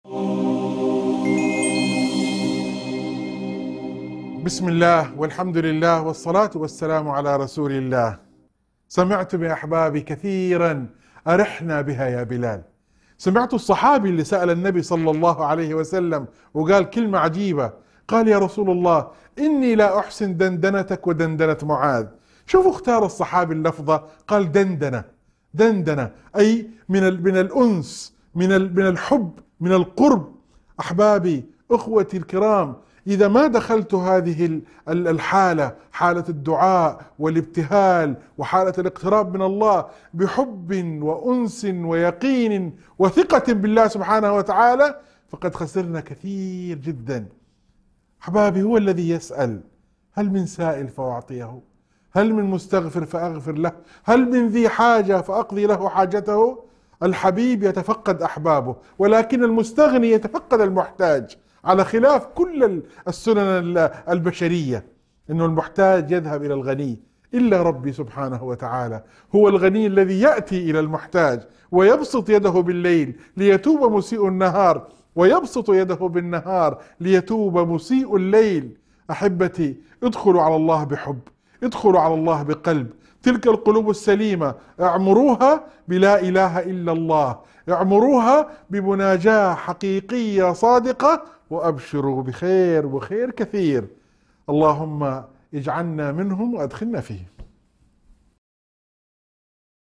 موعظة مؤثرة عن قرب الله من عباده ومحبته لهم، خاصة المحتاجين والداعين. تذكر بفضل الدعاء والأنس بالله وضرورة الإقبال عليه بقلب سليم، مع بشارات الخير لمن يتخلق بهذه الصفات.